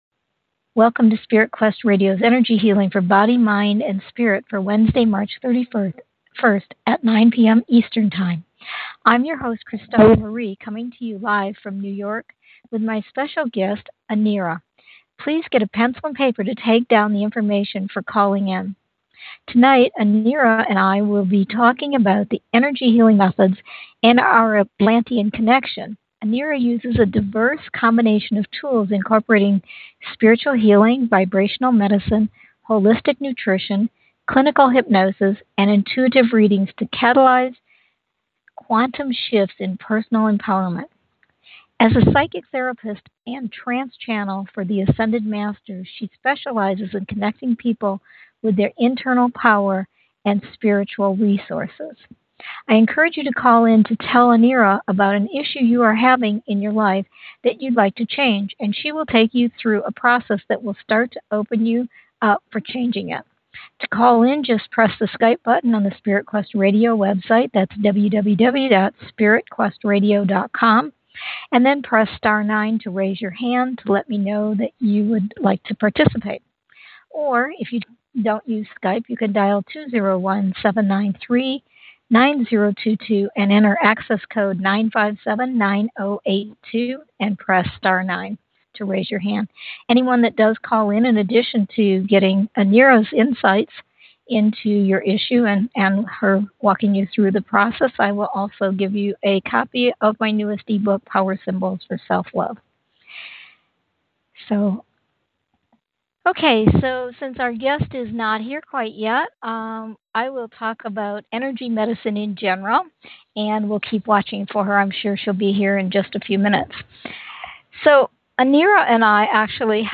The interview was rocked when two call-ins to the show were healing testimonials that were riveting to listen to.
Just click the links below to listen to an excerpted version in 2 parts: